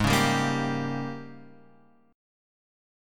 G#M7sus2sus4 chord {4 4 5 3 x 3} chord